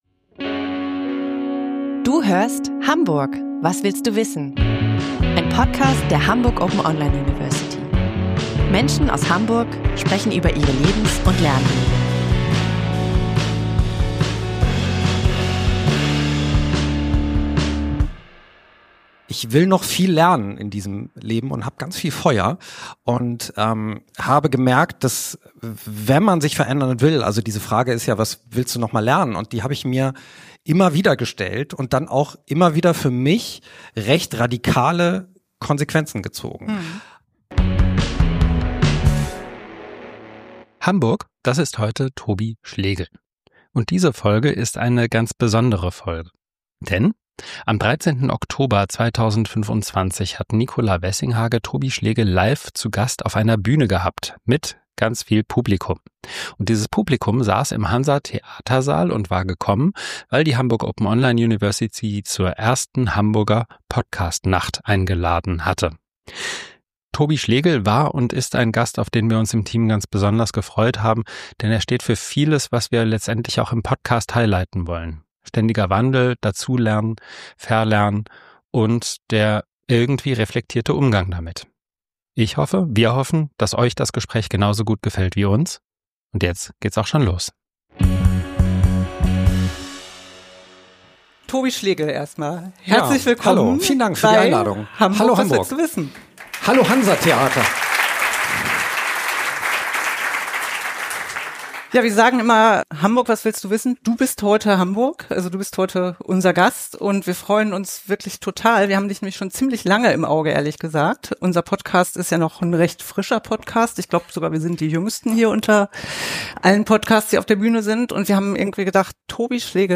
Tobi Schlegl passt perfekt in einen Podcast, in dem es um Veränderung, Lernen und Verlernen geht. Deswegen haben wir uns sehr gefreut, ihn ausgerechnet im Rahmen der ersten Hamburger Podcast-Nacht auf der Bühne im Hansa-Theatersaal willkommen zu heißen.